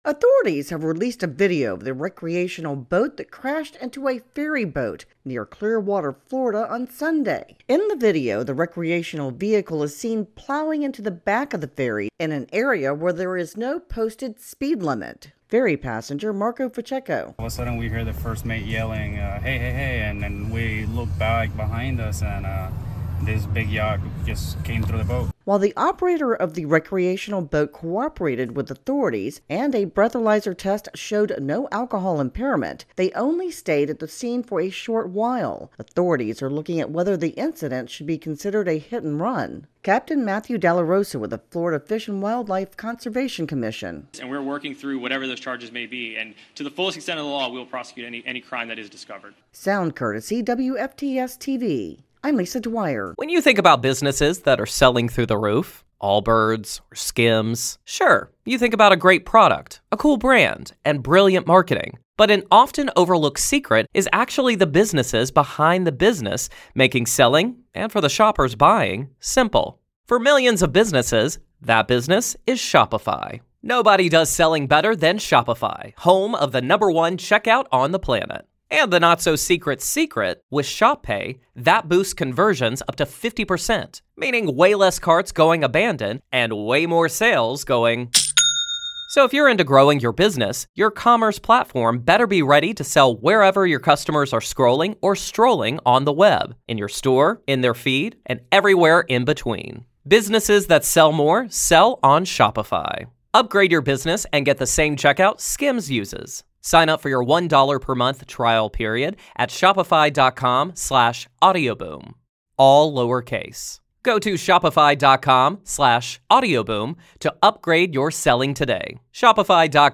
reports on a fatal boat crash off the coast of Floriday.